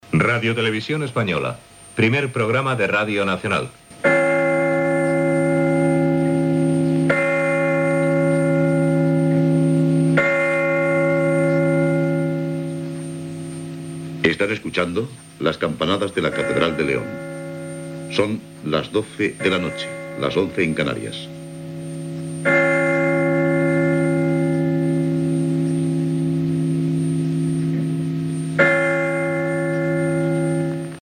Identificació de l'emissora, com RTVE Primer Programa de Radio Nacional, campanades des de la catedral de Lleó i hora
Presentador/a